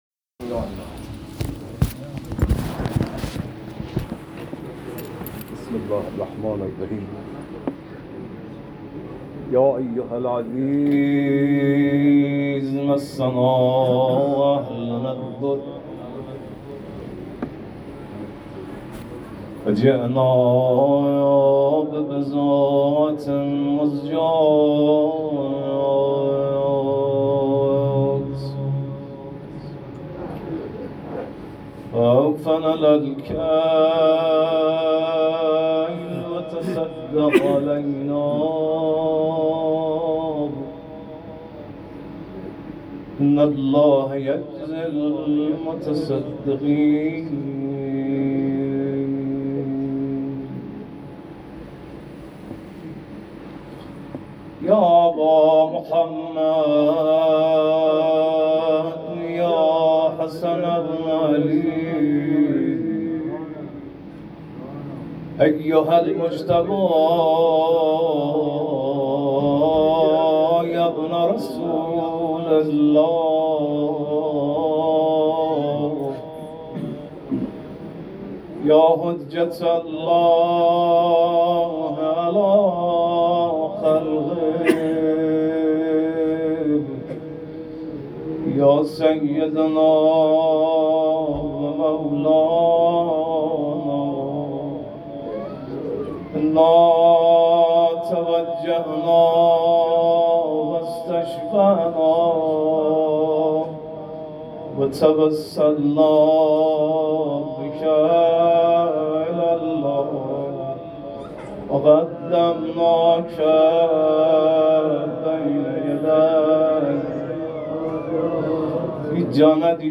شب ششم محرم